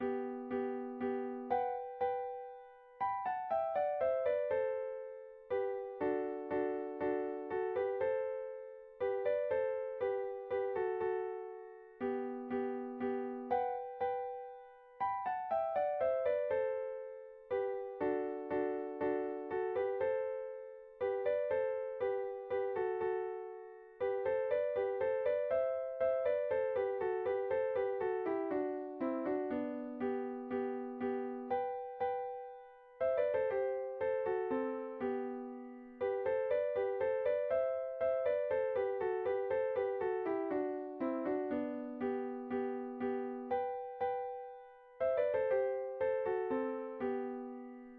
pdmx-multi-instrument-synthesized